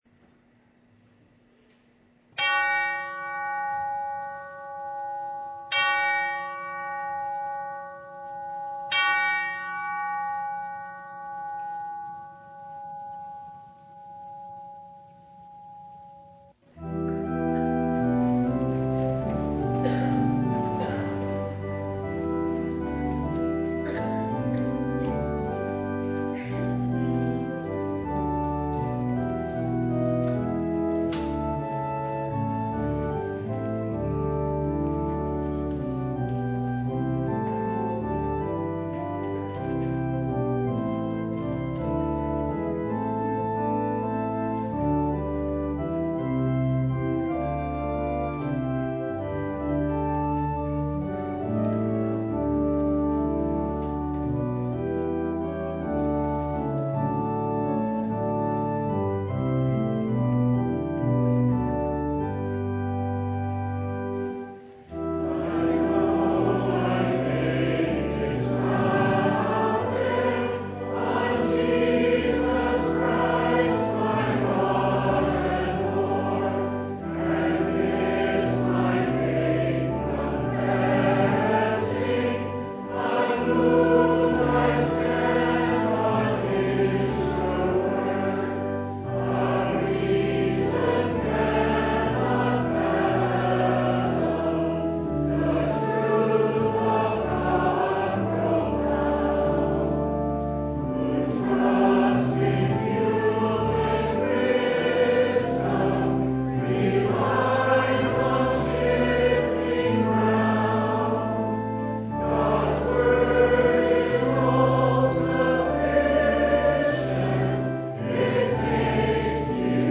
Preacher: Visiting Pastor Passage: Mark 7:14-23 Service Type: Sunday
Download Files Notes Bulletin Topics: Full Service « Proverbs